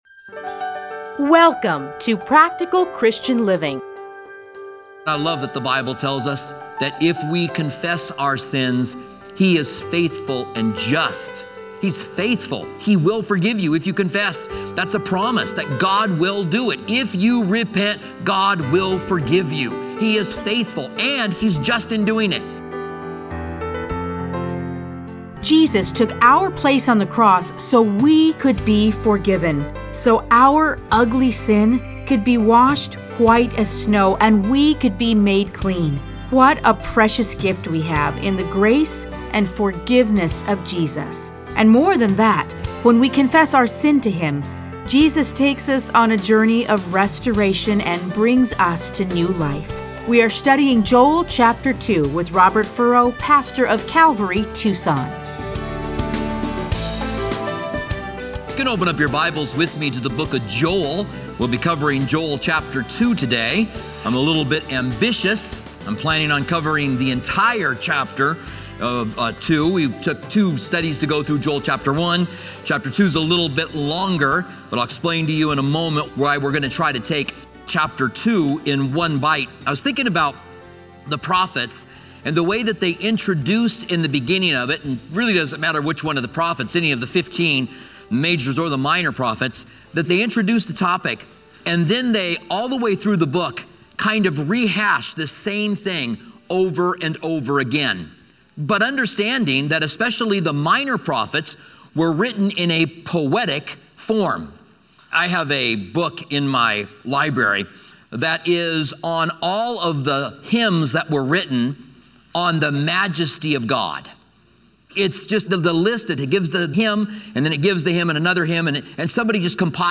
Listen to a teaching from Joel 2:1-17, 21-27.